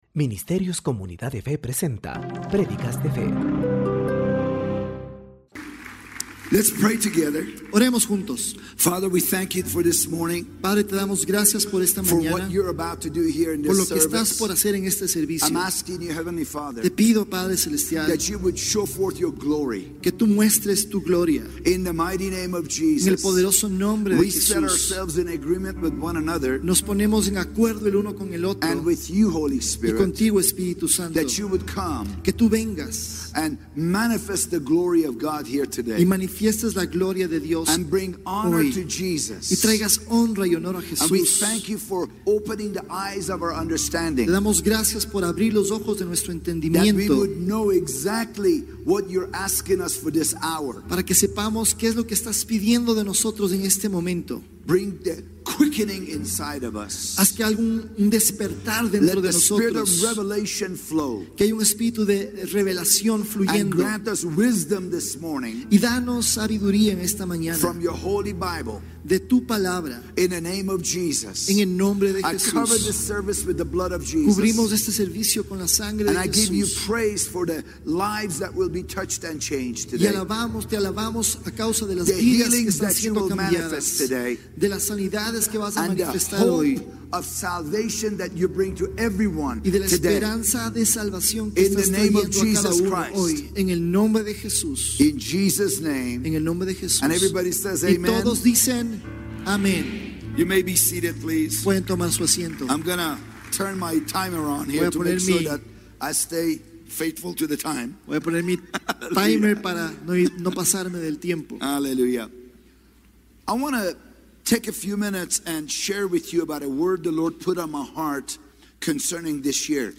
Prédicas Semanales - Comunidad de Fe